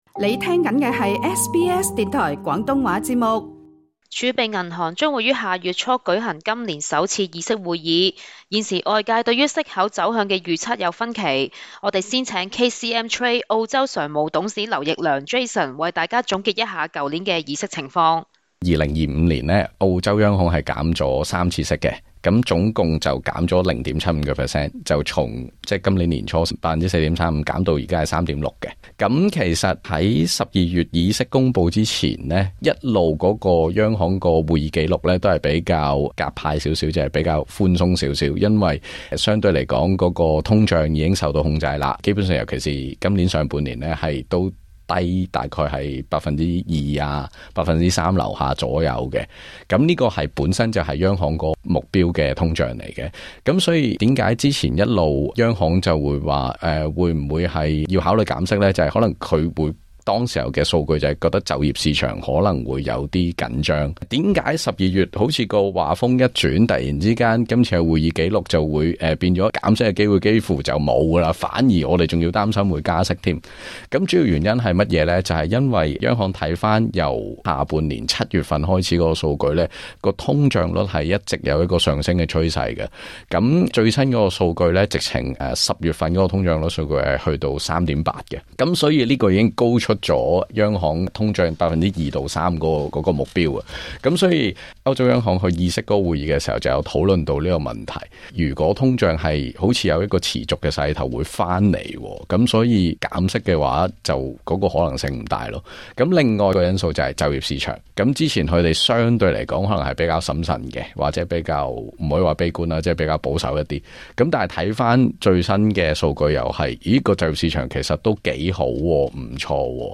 更多內容，請收聽錄音訪問。